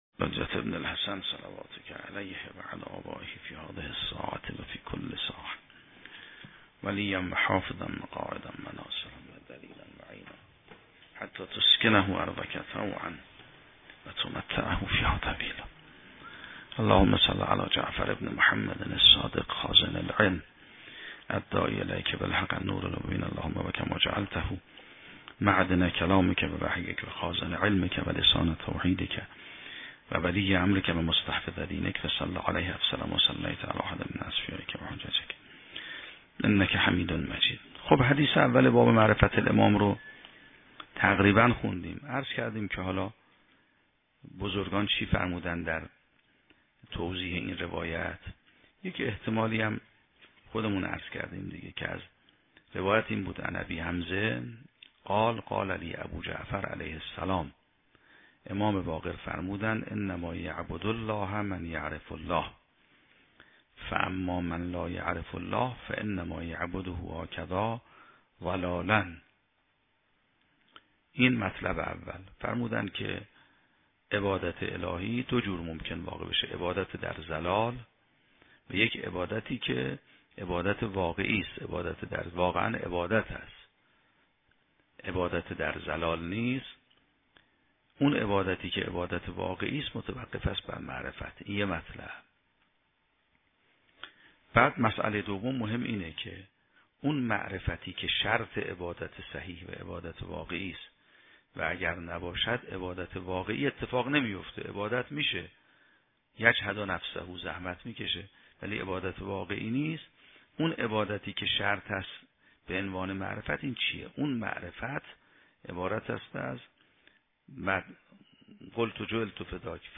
شرح و بررسی کتاب الحجه کافی توسط آیت الله سید محمدمهدی میرباقری به همراه متن سخنرانی ؛ این بخش : معرفت الامام راه رسیدن به معرفه الله - اهمیت تصدیق الله و الرسول در مسیر معرفت الله